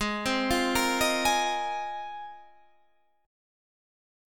Ab6add9 chord